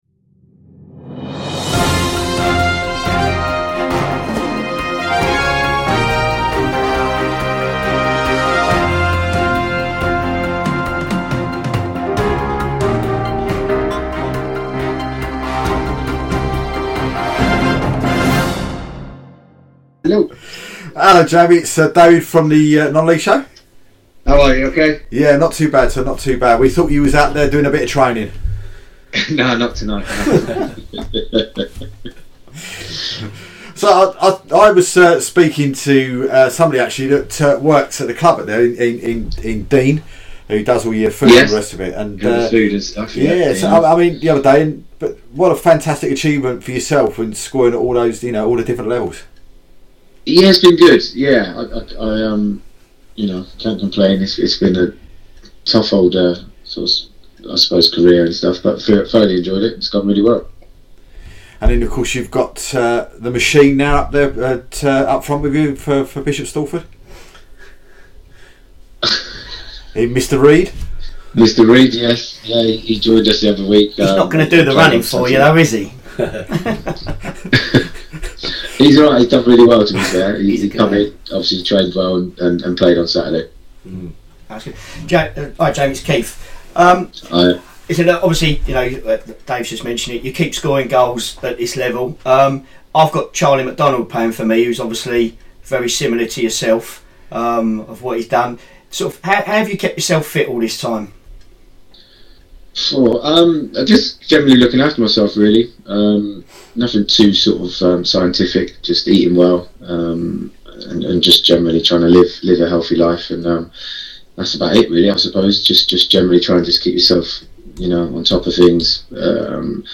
The D&K Reposs Non League Show Jamie Cureton Interview 20/01/20